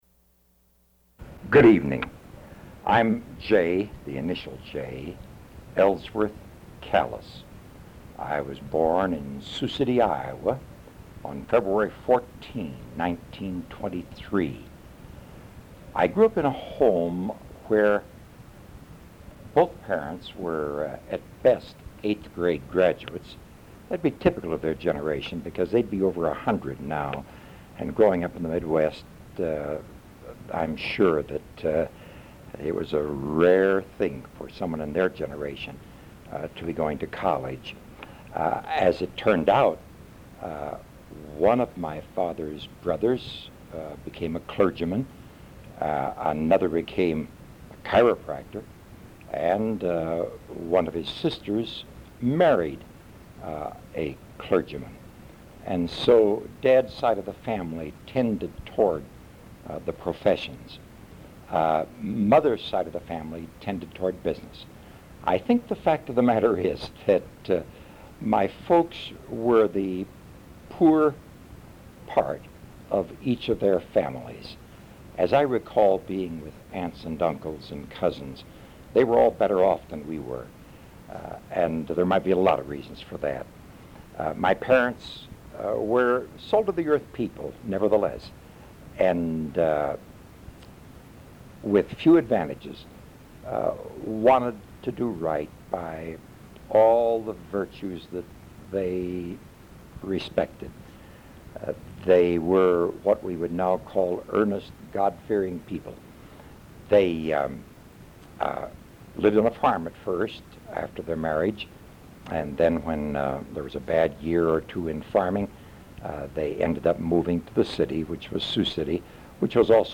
Oral History Interview